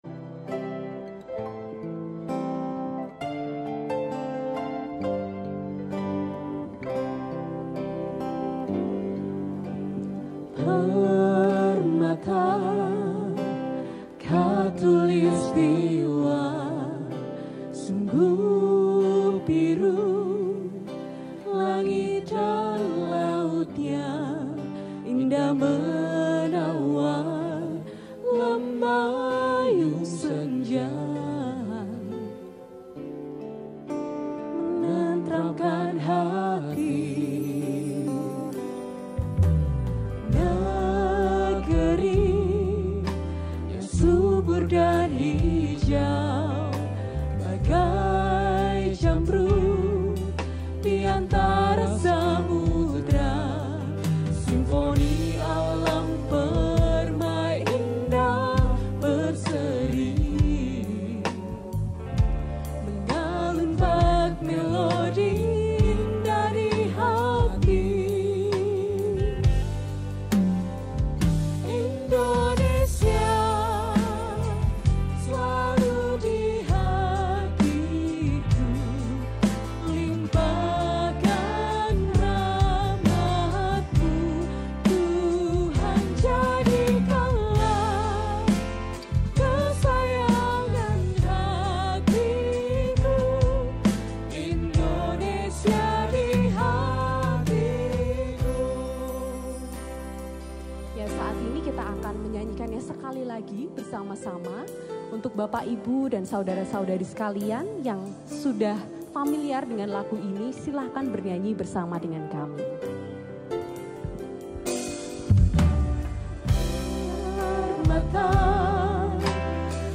Bukan Lautan Hanya Kolam Susu – Ibadah Minggu (Sore 5 p.m)